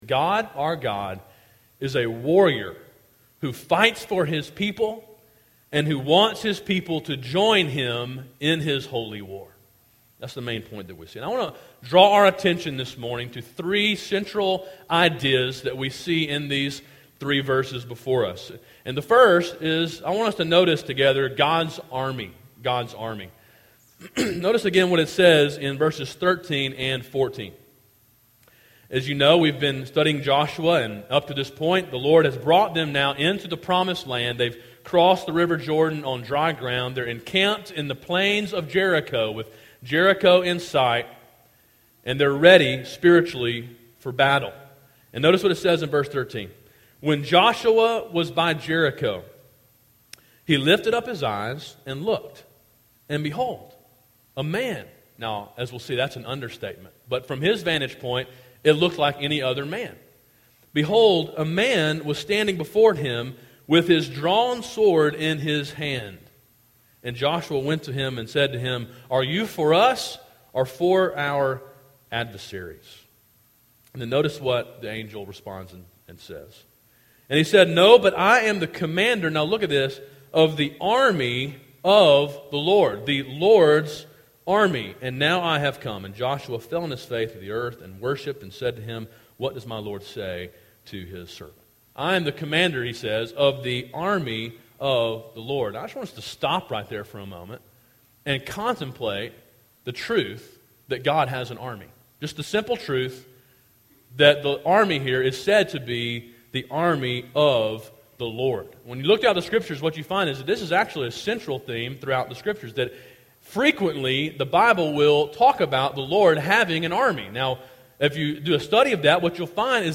A sermon in a series on the book of Joshua.